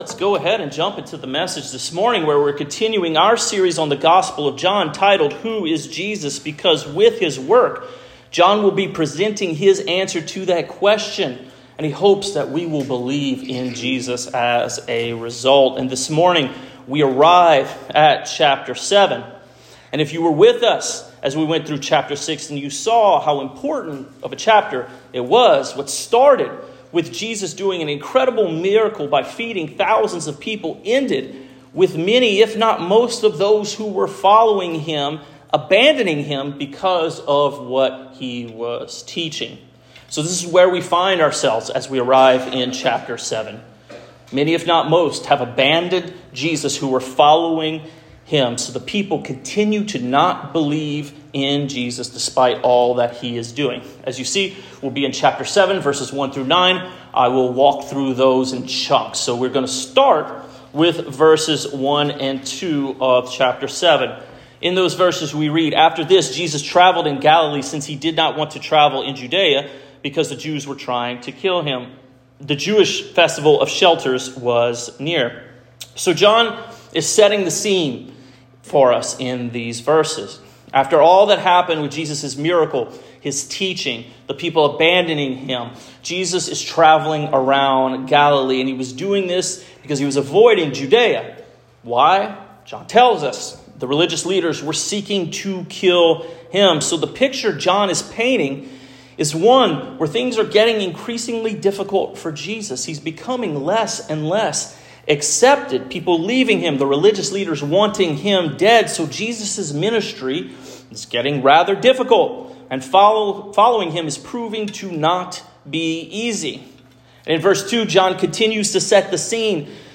Sermons | Fellowship Baptist Church
We apologize for the misaligned audio during the video